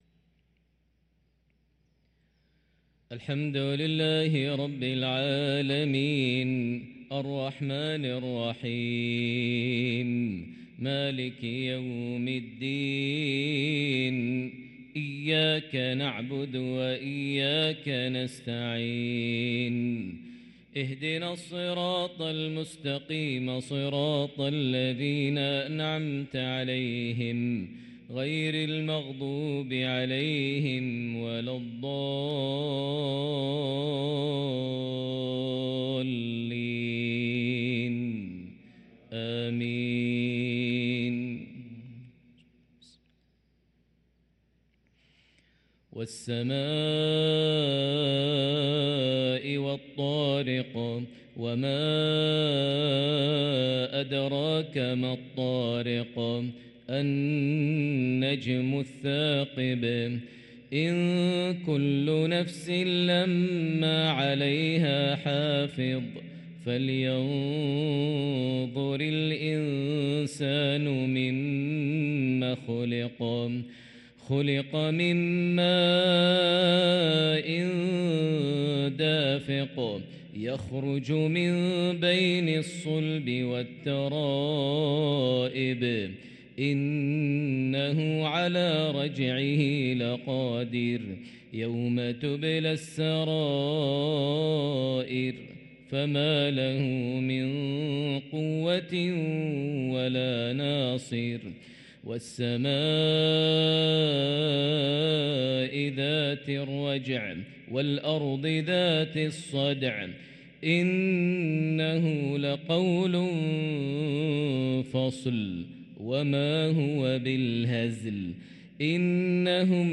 صلاة المغرب للقارئ ماهر المعيقلي 2 ربيع الآخر 1445 هـ
تِلَاوَات الْحَرَمَيْن .